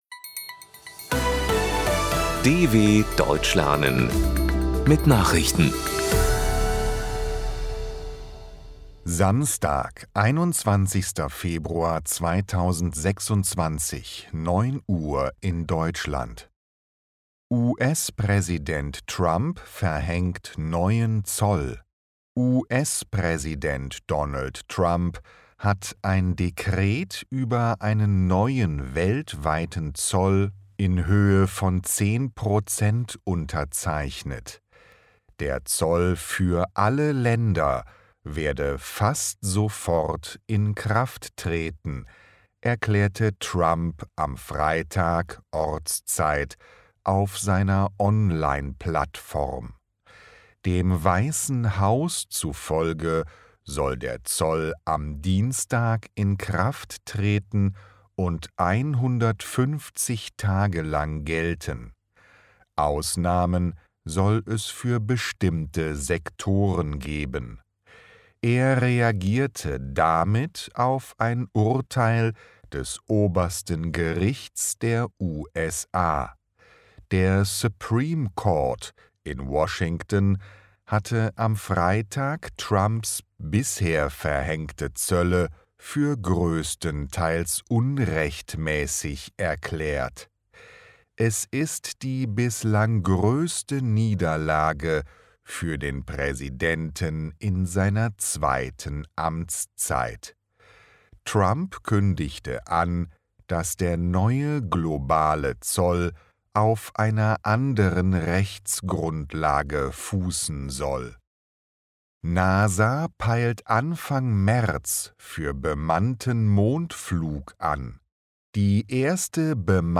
21.02.2026 – Langsam Gesprochene Nachrichten
Trainiere dein Hörverstehen mit den Nachrichten der DW von Samstag – als Text und als verständlich gesprochene Audio-Datei.